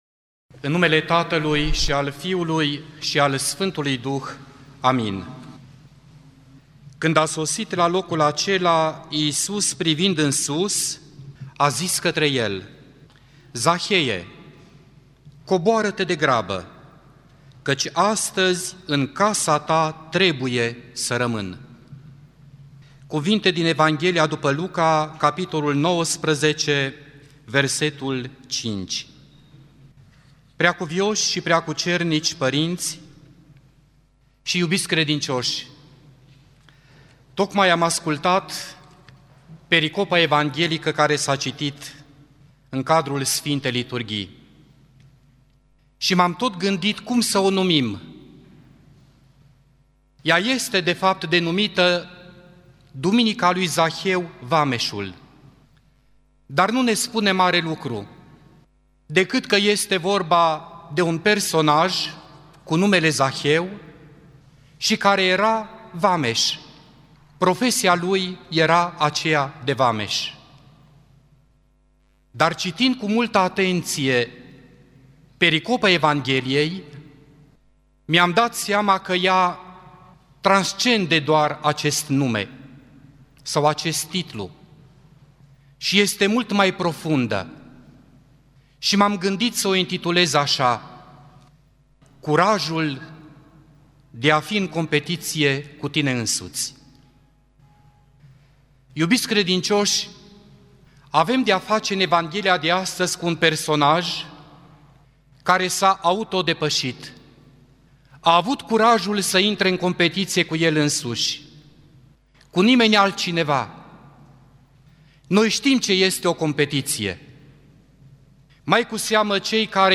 Predică la Duminica a 32-a după Rusalii (a lui Zaheu)